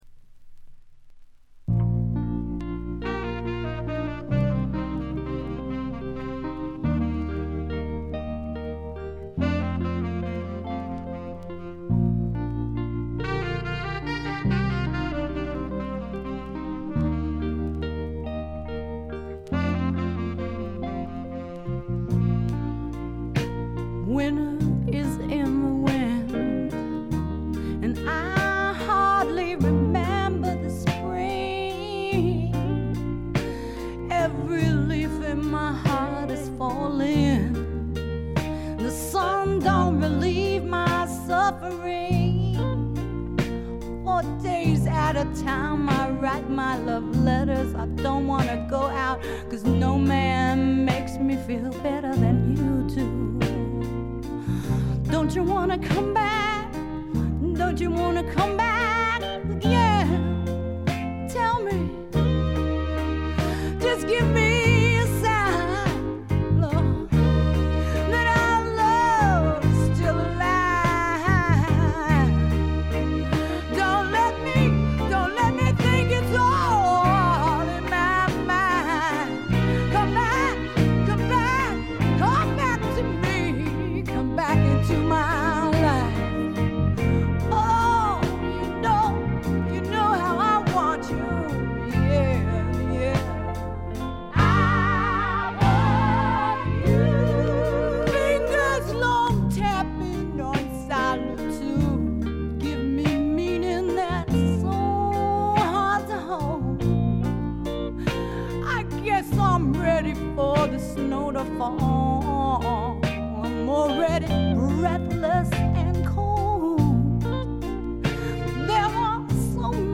部分試聴ですが、わずかなノイズ感のみ。
ファンキーでタイト、全編でごきげんな演奏を繰り広げます。
試聴曲は現品からの取り込み音源です。
Recorded At - The Sound Factory